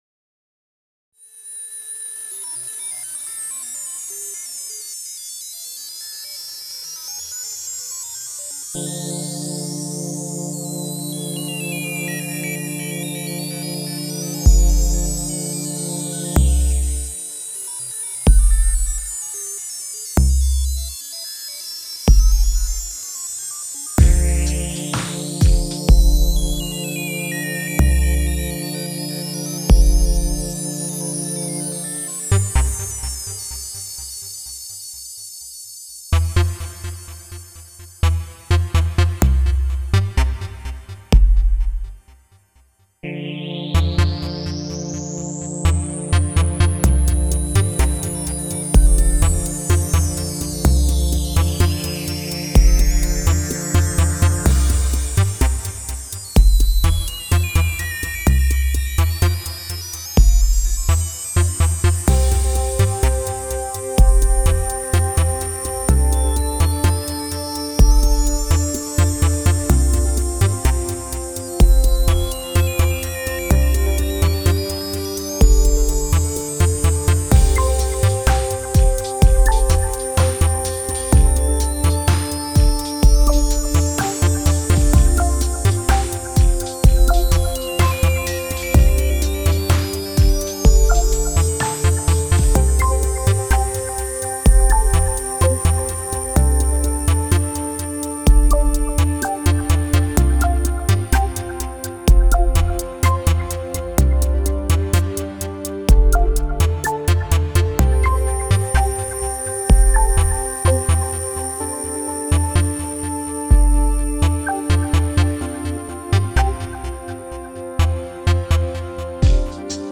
Genre Electronica